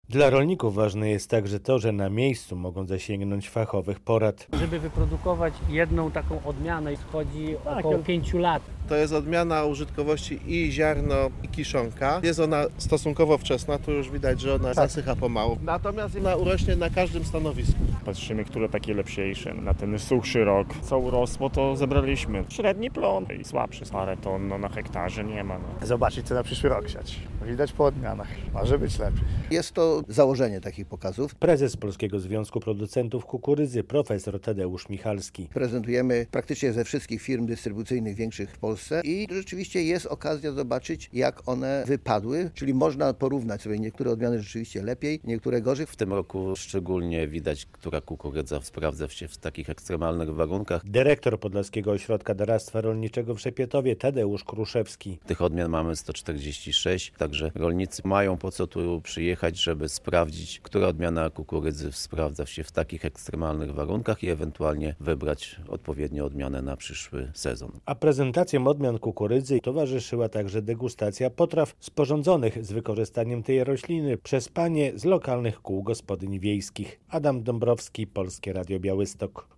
23. Krajowy Dzień Kukurydzy w Szepietowie - relacja